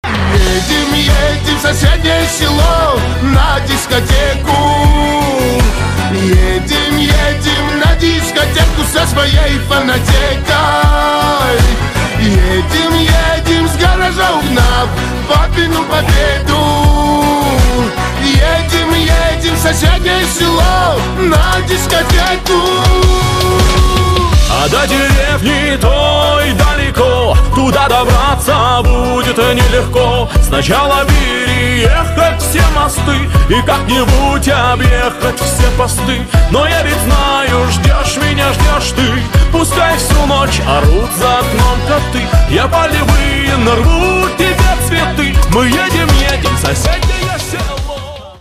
• Качество: 320, Stereo
мужской вокал
дуэт
кавказские
шансон